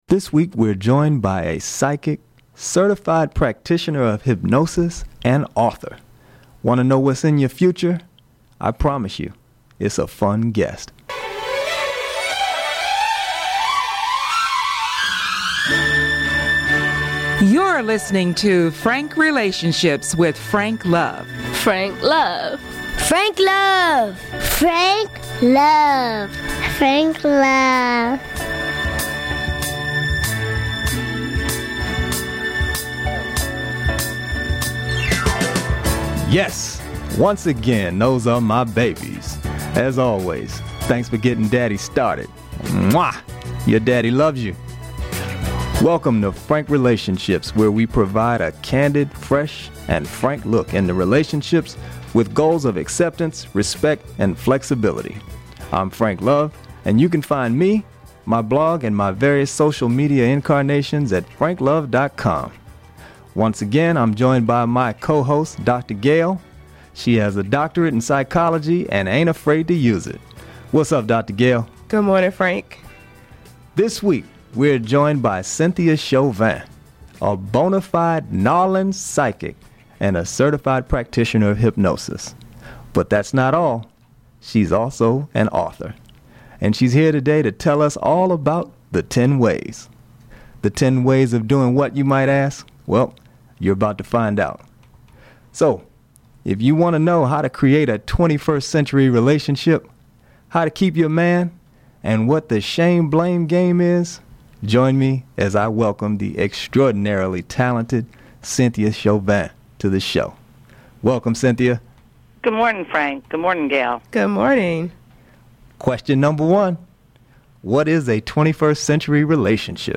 Podcast Episode: This week we’re joined by a Psychic, Certified Practitioner of Hypnosis, and author.